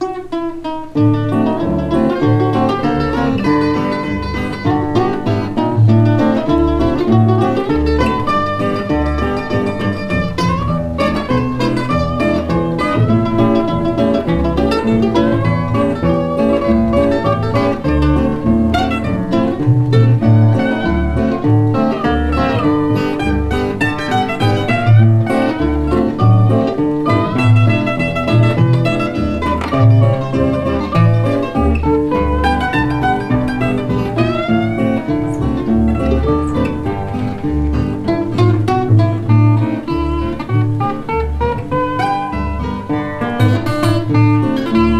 Jazz　USA　12inchレコード　33rpm　Stereo